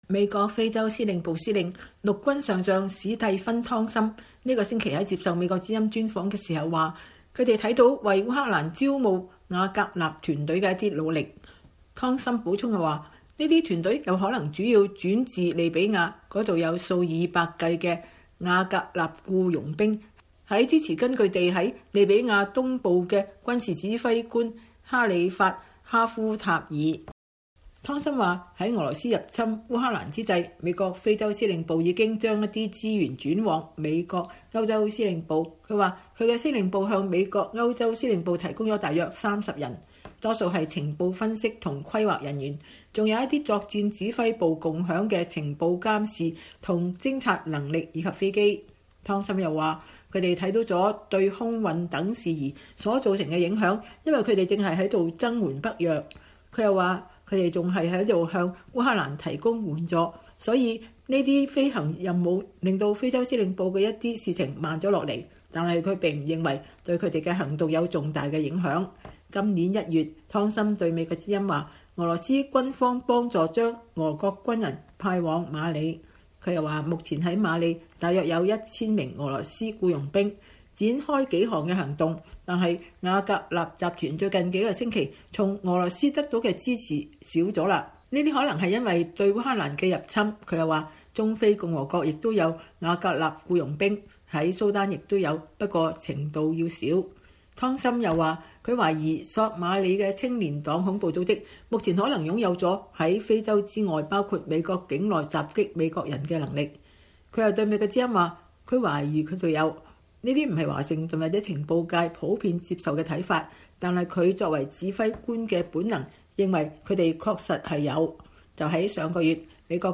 專訪美軍司令：瓦格納集團在非洲為侵烏俄軍招募僱傭兵 赤道幾內亞無計劃建中國軍事基地